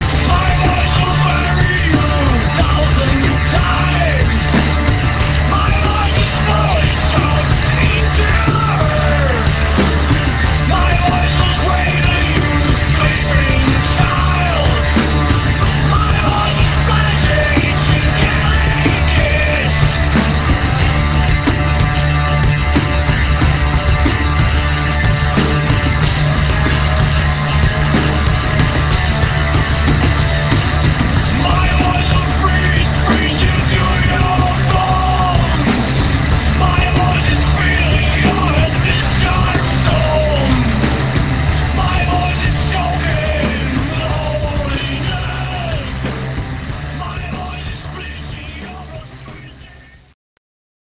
146 kB MONO